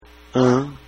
Давайте прослушаем произношение этих звуков:
ã (носовое “a”) -